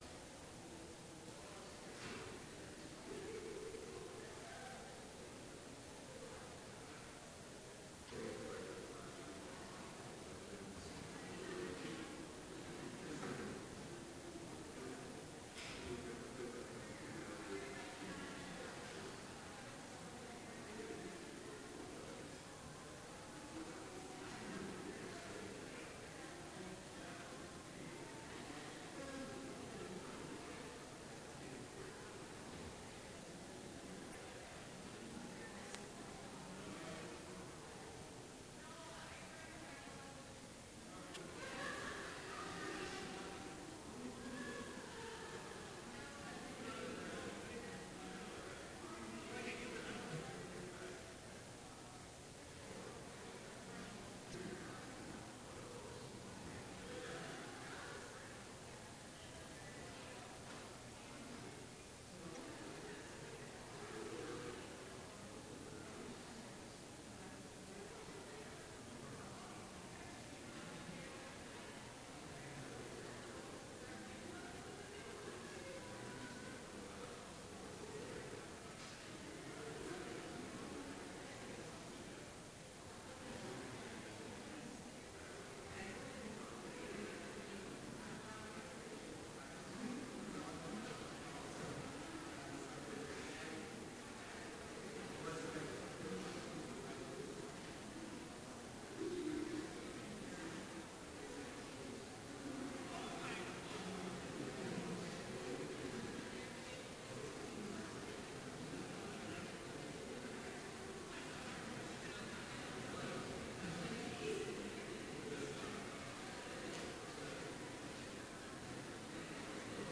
Complete service audio for Chapel - February 22, 2017